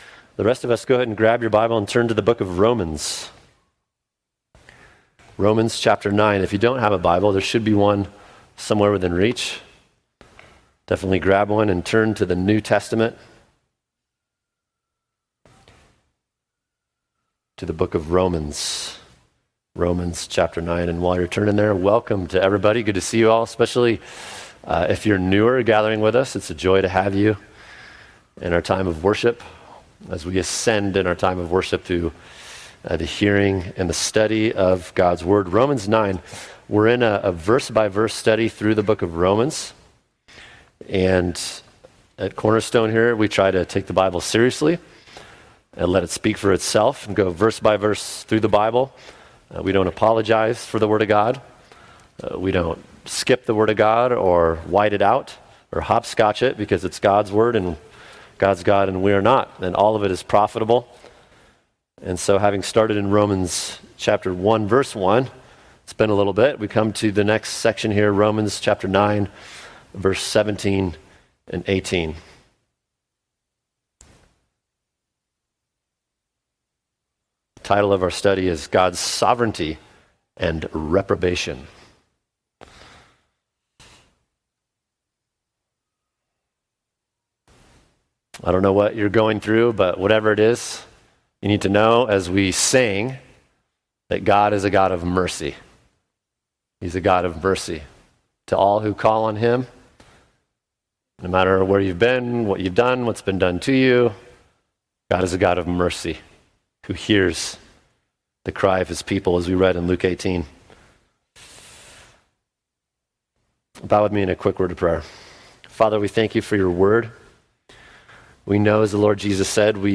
[sermon] God’s Sovereignty & the Unrepentant | Cornerstone Church - Jackson Hole